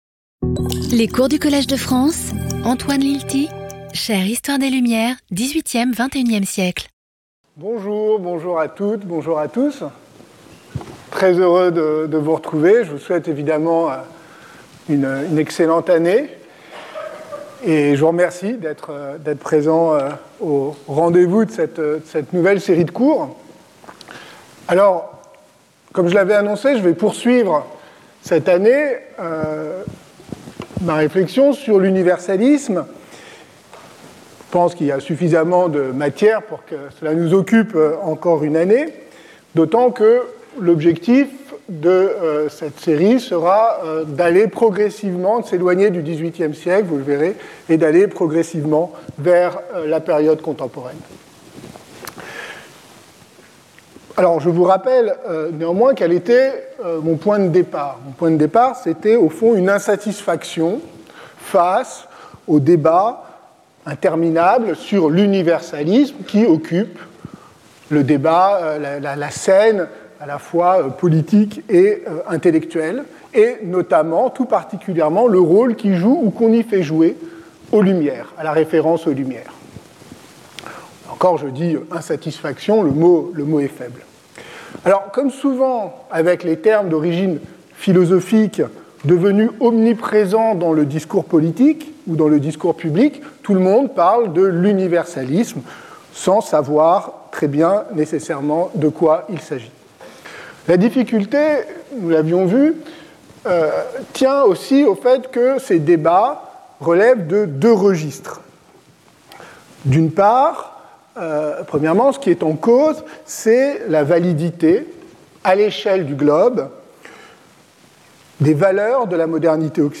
This introductory session begins with a reminder that current debates on " l' universalisme " combine two registers : the place of Western modern values in a globalized and plural world, on the one hand ; the place of minority groups and multiple identities in modern liberal societies, on the other.